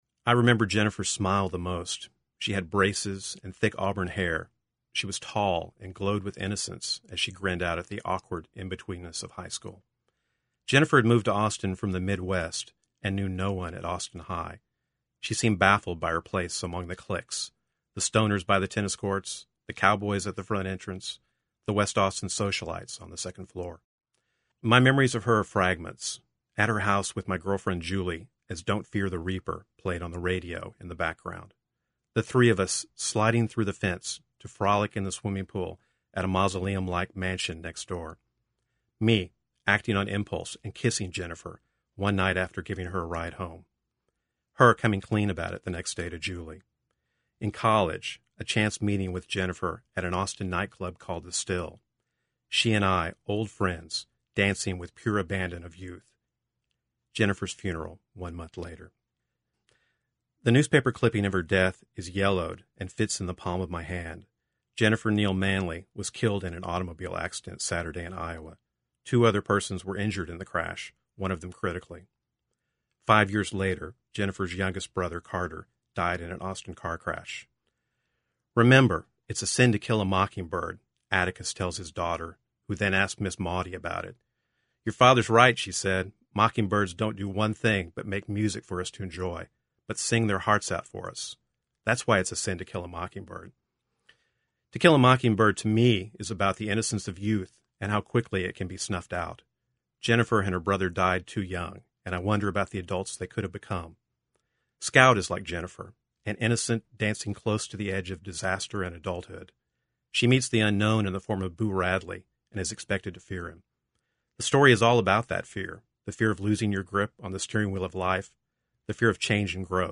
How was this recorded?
Ten years ago I was asked by KUT radio in Austin to write a remembrance of Harper Lee's classic book To Kill a Mockingbird. Then I read it on the air.